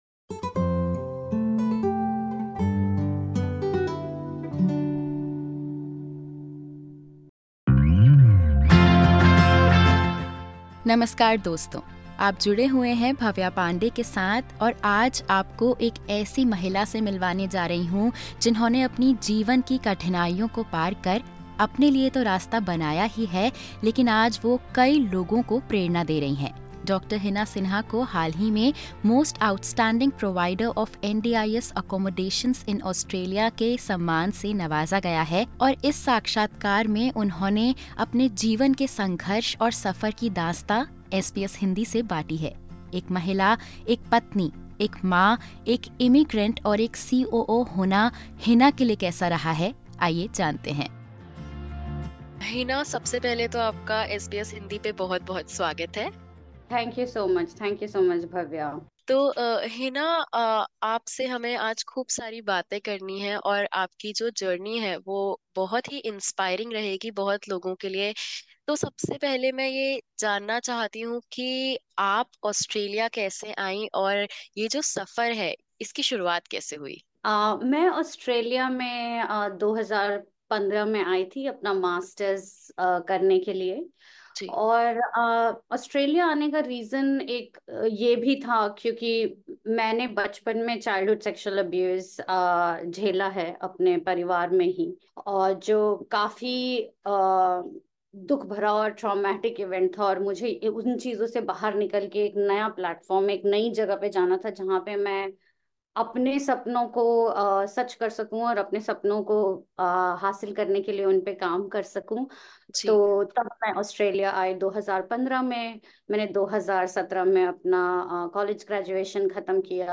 In this podcast, SBS Hindi interviews